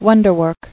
[won·der·work]